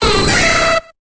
Cri de Debugant dans Pokémon Épée et Bouclier.